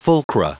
Prononciation audio / Fichier audio de FULCRA en anglais
Prononciation du mot : fulcra